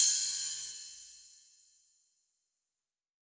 9RIDE2.wav